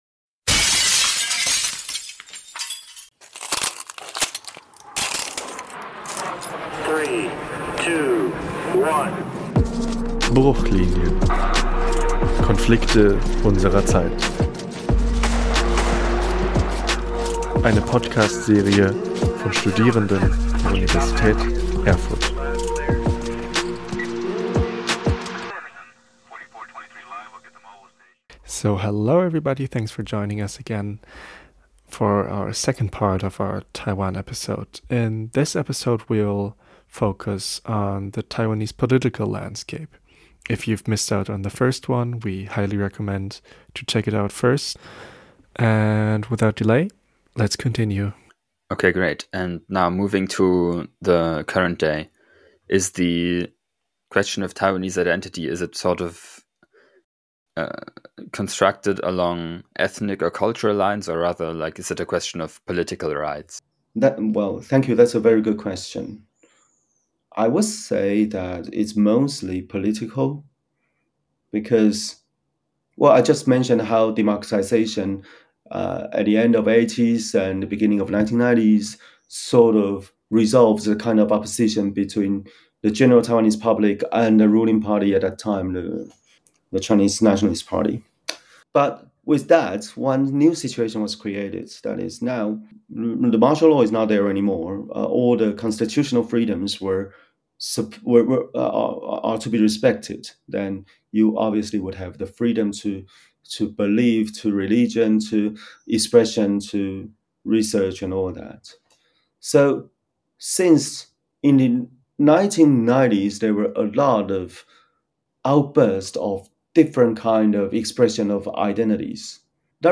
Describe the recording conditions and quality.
Ultima Irratio' is a project of the FSR Staatswissenschaften of the University of Erfurt, financed by the Studienrat.